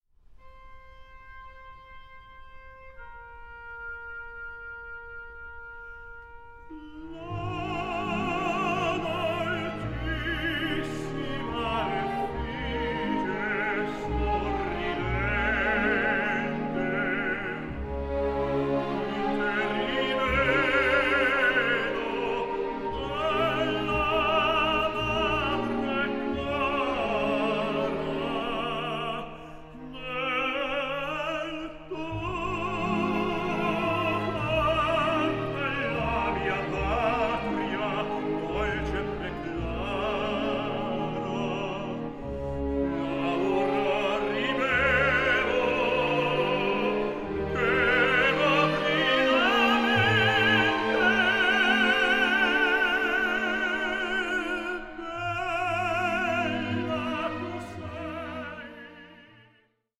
HEART-WRENCHING VERISMO ARIAS